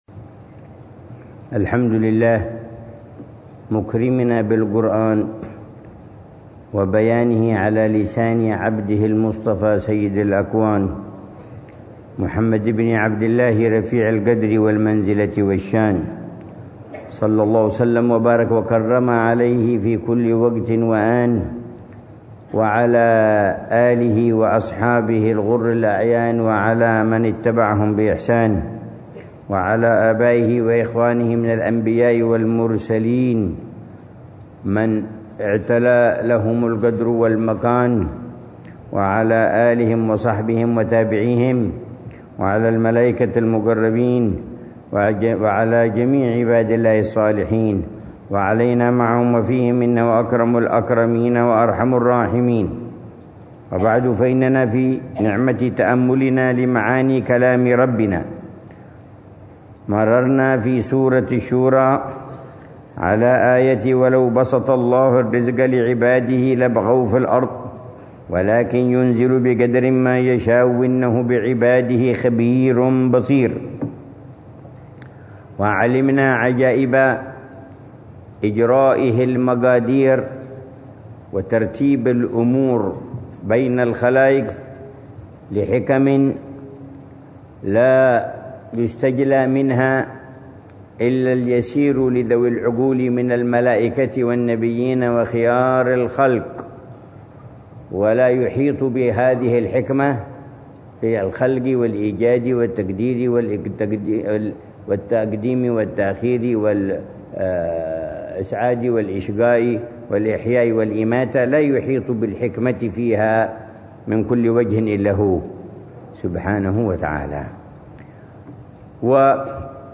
الدرس العاشر من تفسير العلامة عمر بن محمد بن حفيظ للآيات الكريمة من سورة الشورى، ضمن الدروس الصباحية لشهر رمضان المبارك من عام 1446هـ